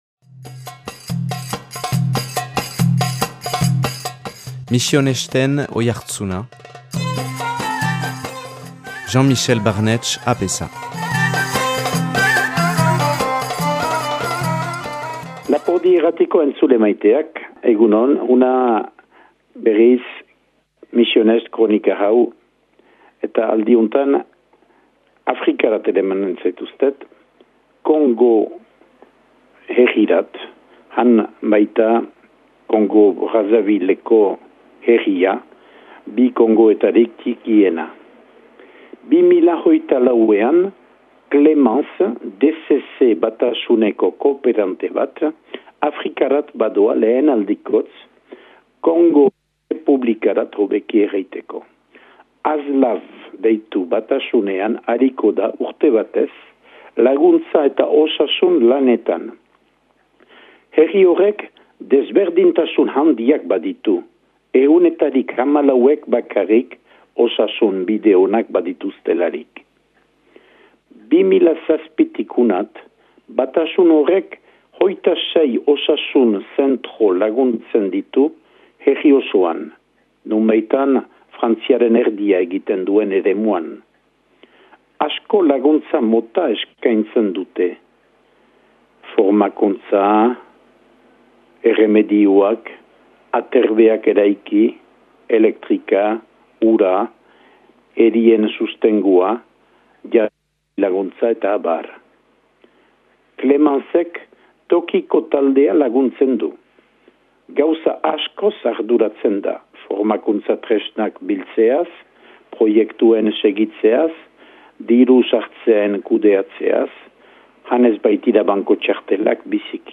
Kongoko errepublikan, DCC boluntario baten lekukotasuna